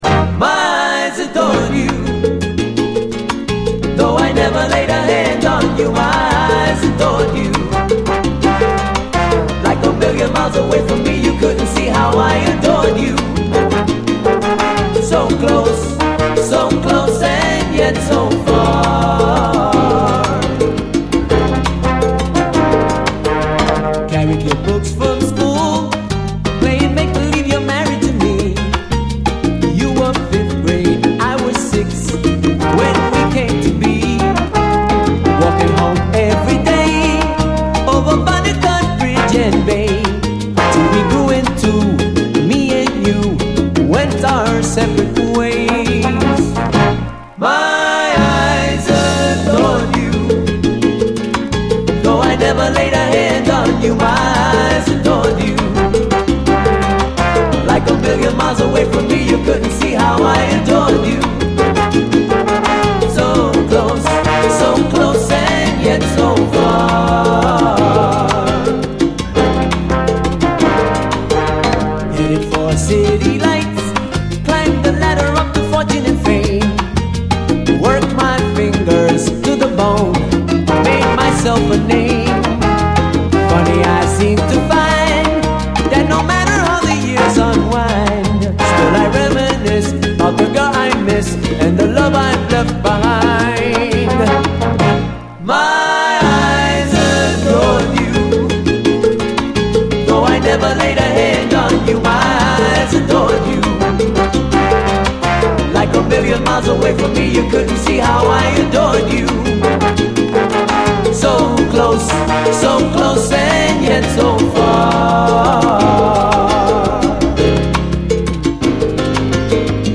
SALSA, LATIN
傑作イングリッシュ・ロマンティック・サルサ